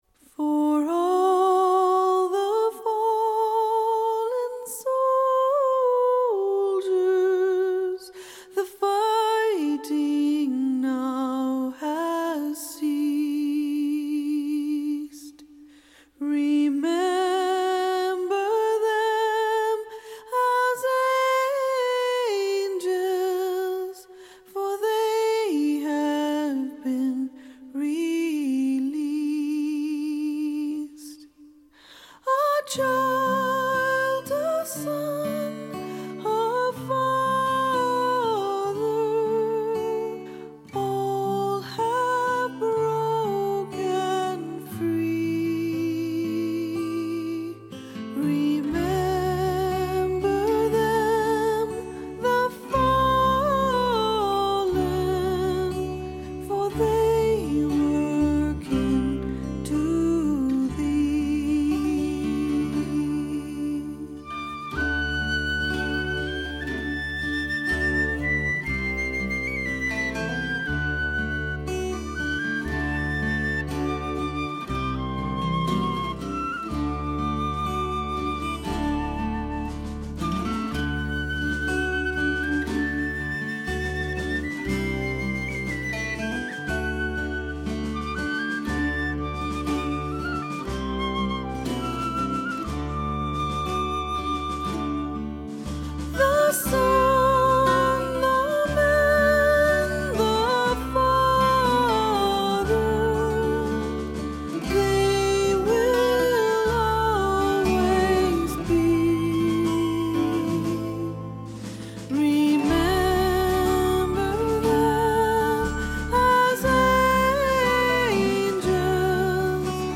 ★1990 年代人手一張的女聲測試片，以原始母帶精心重製，收錄三首原版未收錄之新曲！